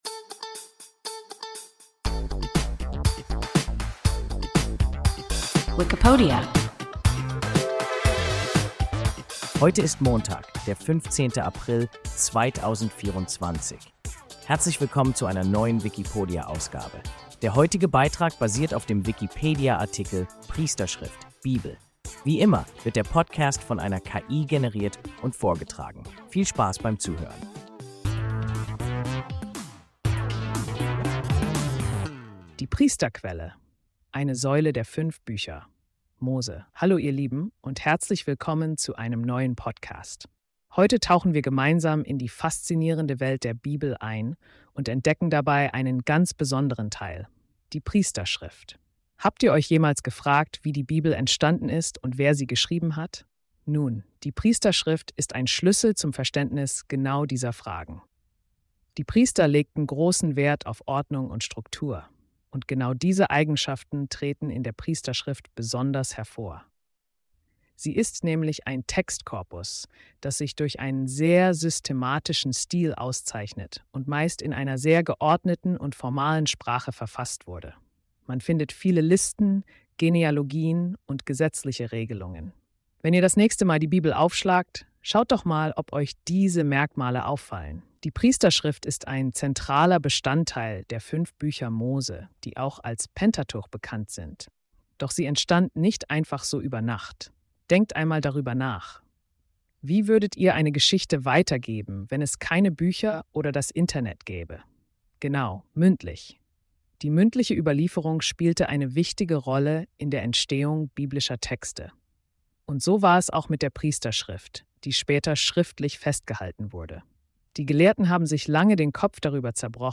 Priesterschrift (Bibel) – WIKIPODIA – ein KI Podcast